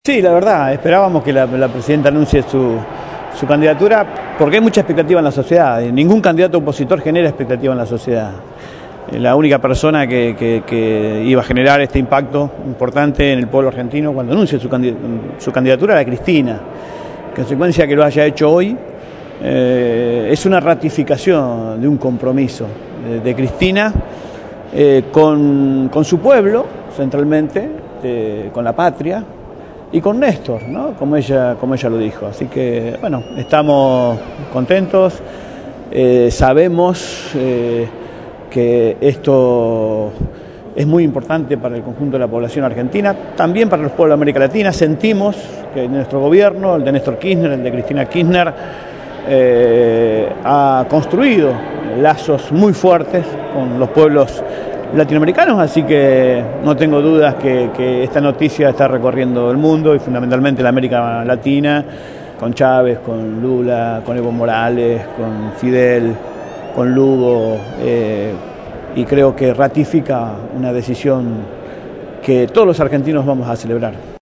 registró para los micrófonos de Radio Gráfica FM 89.3 los testimonios de la jornada.